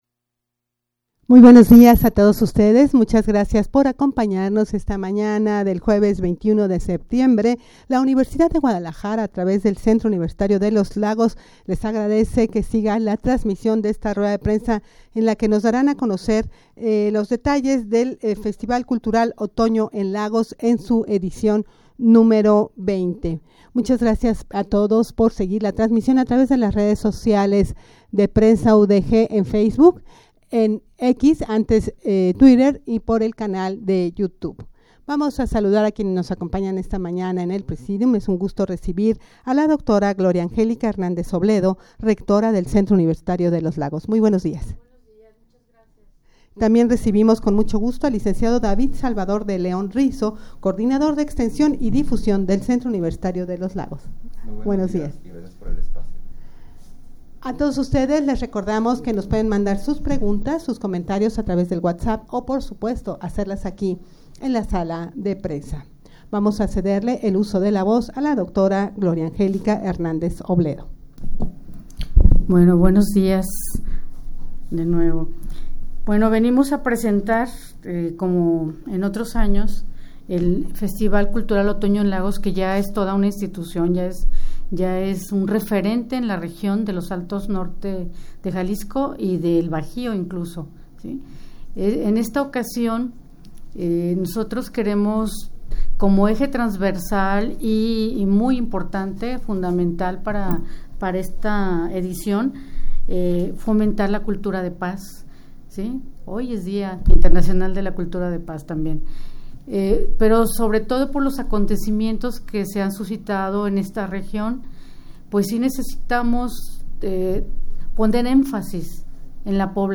Audio de la Rueda de Prensa
rueda-prensa-para-dar-a-conocer-el-xx-festival-cultural-otono-en-lagos-un-espacio-de-fomento-para-la-cultura-de-paz.mp3